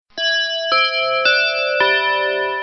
chime_notice.ogg